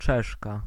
Ääntäminen
Synonyymit Czechic Czechian Czechish Ääntäminen US : IPA : [ʧɛk] Tuntematon aksentti: IPA : /tʃɛk/ Lyhenteet ja supistumat Cz.